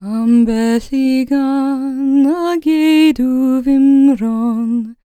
L CELTIC A01.wav